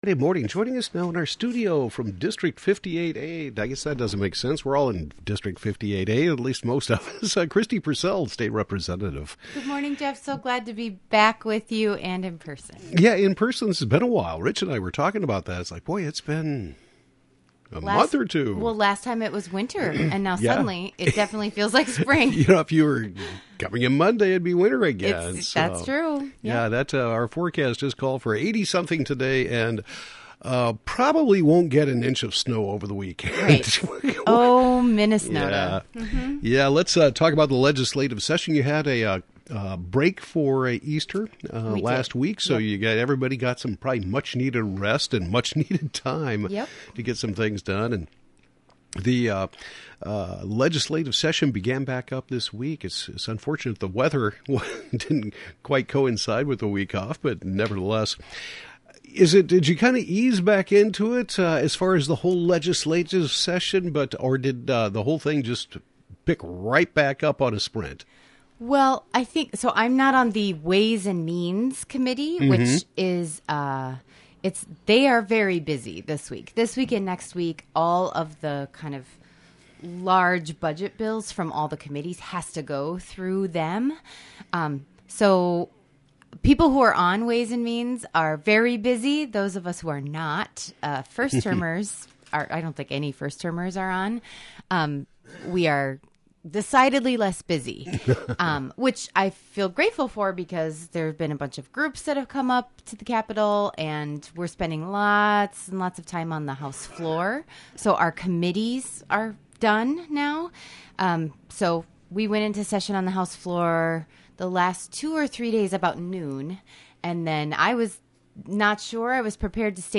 District 58A Representative Kristi Pursell provides her weekly legislative update including passage of House File 3: Democracy for the People Bill.